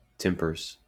Ääntäminen
Ääntäminen US Haettu sana löytyi näillä lähdekielillä: englanti Käännöksiä ei löytynyt valitulle kohdekielelle. Tempers on sanan temper monikko.